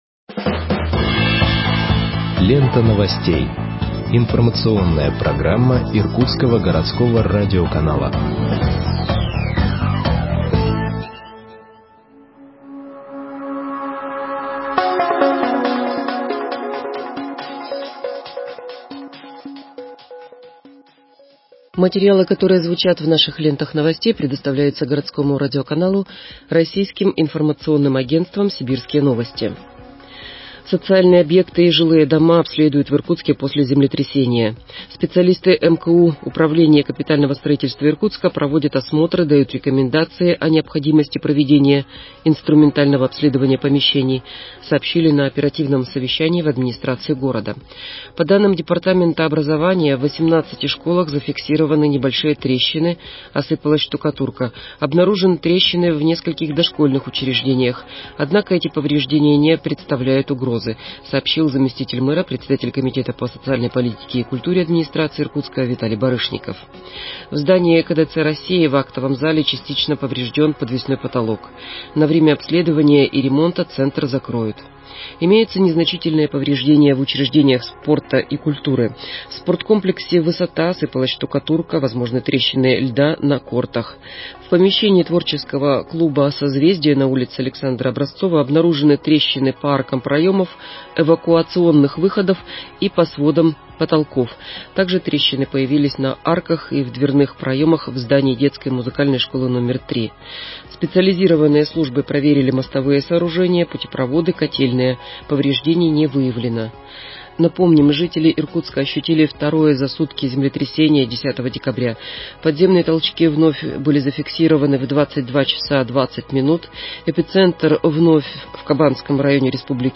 Выпуск новостей в подкастах газеты Иркутск от 11.12.2020 № 2